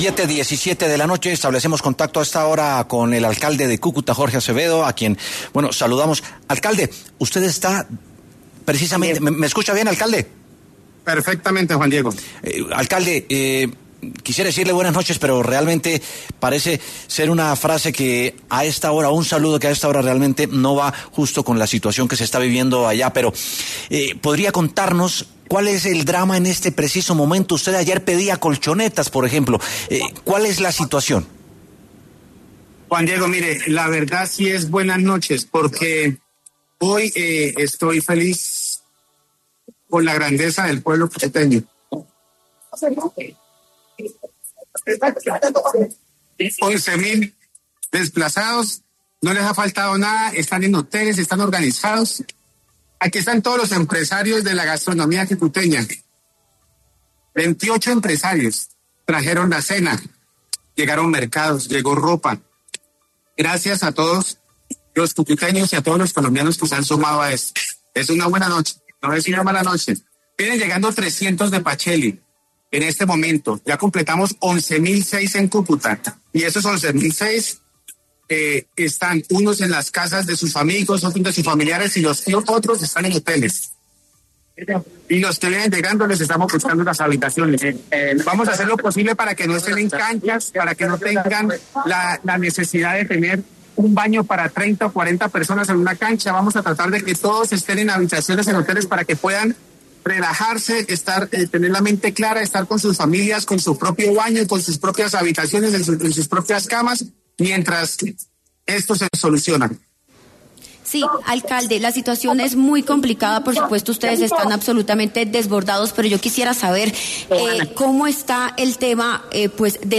Jorge Acevedo, alcalde de Cúcuta, capital de ese departamento y ciudad que ha recibido a gran cantidad de desplazados, habló en W Sin Carreta sobre la coyuntura.